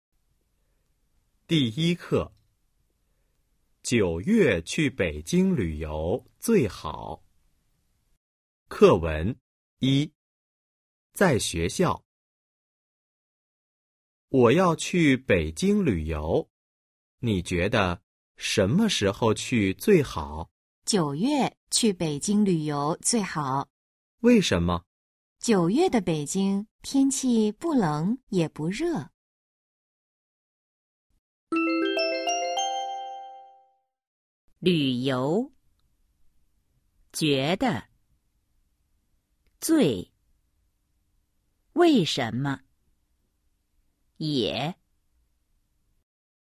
Bài hội thoại 1: 🔊 在学校 – Ở trường  💿 01-01